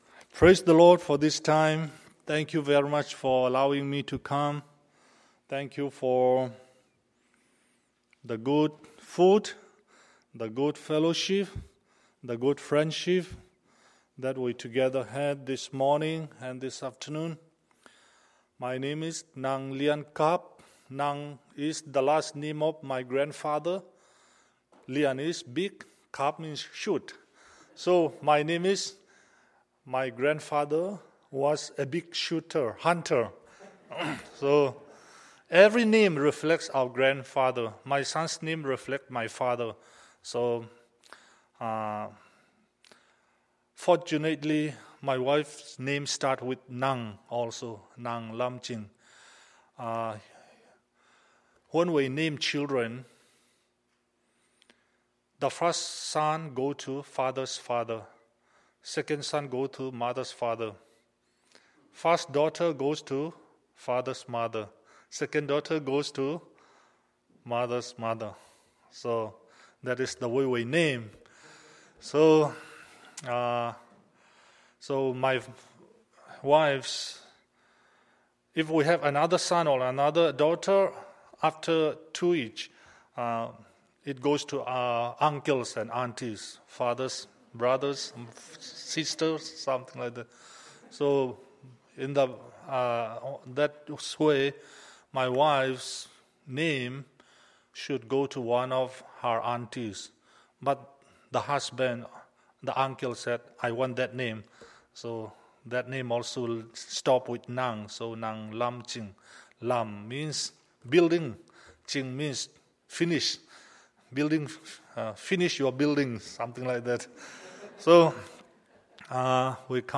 Monday, September 23, 2013 – Evening Service